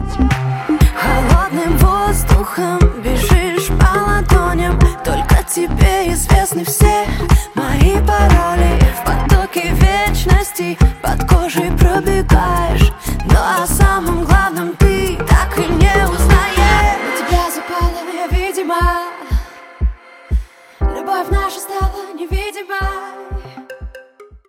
• Качество: 128, Stereo
поп
deep house
dance